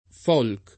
folk [ingl. ^uk; italianizz.